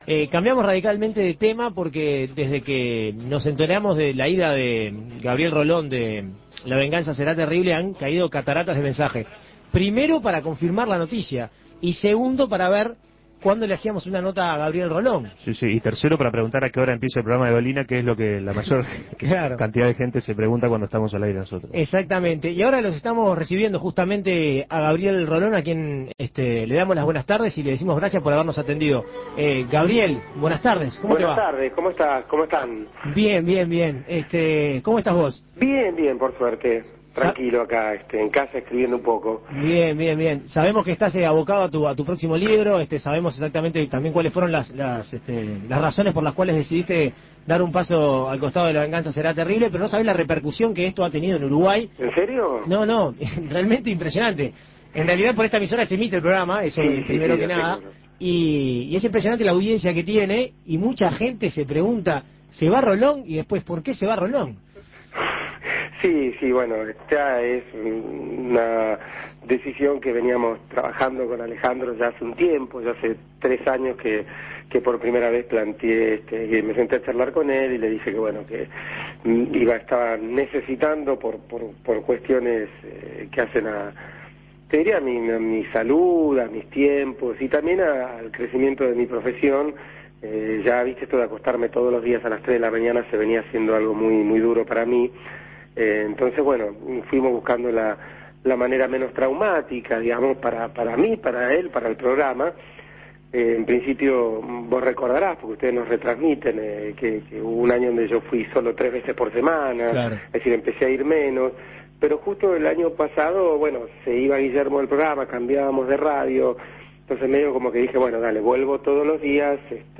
El compañero de fórmula de Alejandro Dolina explicó su decisión en entrevista con 13ª0. Escuche la entrevista.